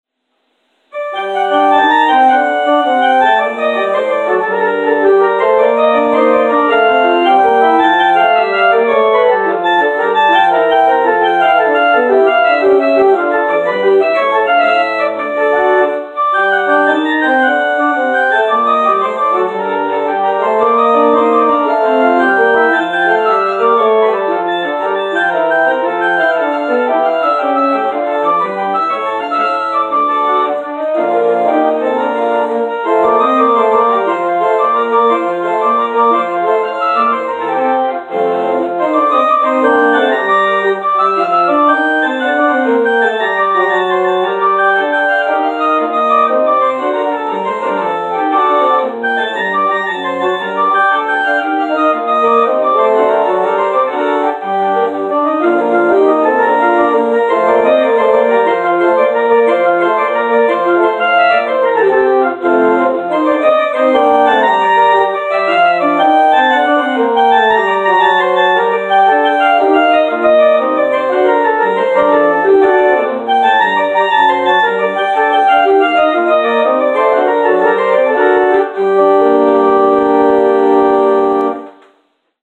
Postlude: “Gigue” – Francois Couperin